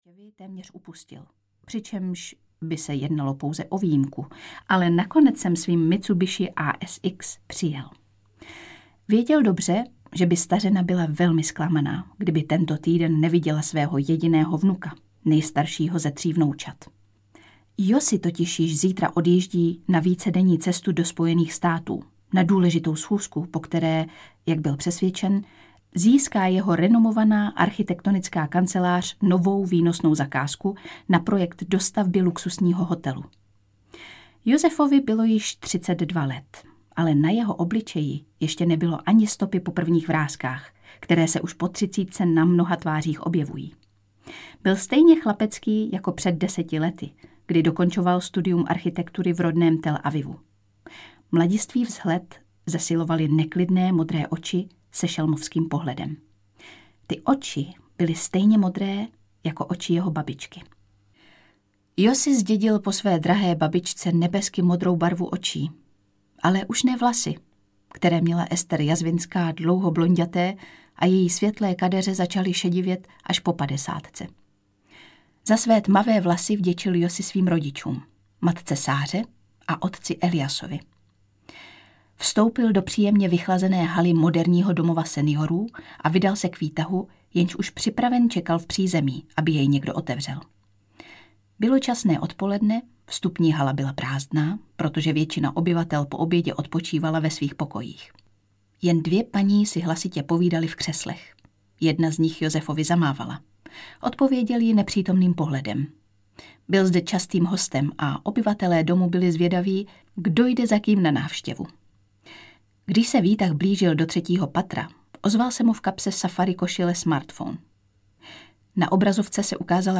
Esterčini otcové audiokniha
Ukázka z knihy
• InterpretLucie Vondráčková